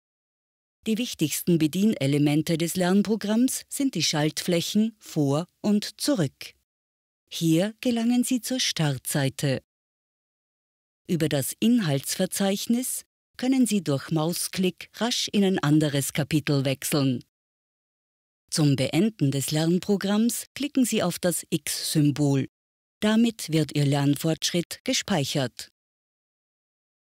Die Stimme ist bekannt aus zahlreichen Werbeproduktionen, sehr variantenreich von seriös, entspannt erzählerisch bis frech, sinnlich, warm.
Sprechprobe: eLearning (Muttersprache):
She can sound deep and trustworthy but also very energetic.